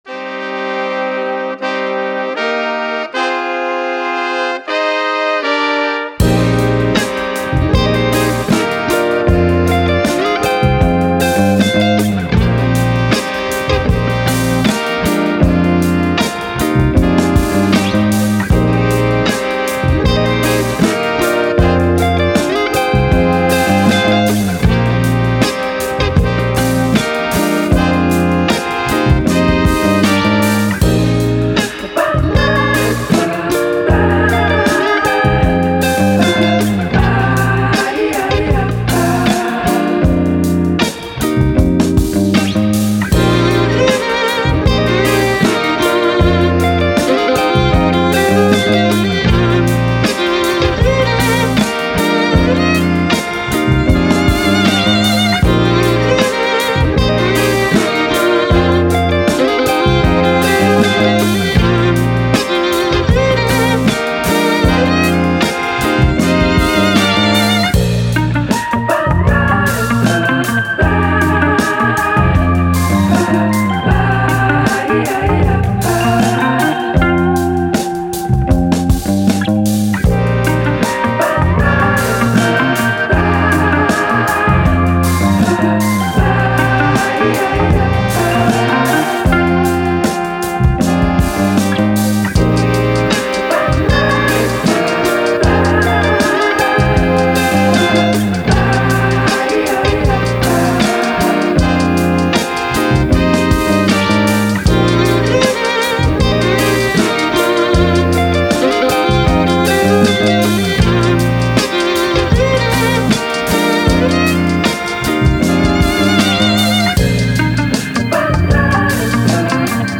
Soul, Vintage, Happy, Positive, Brass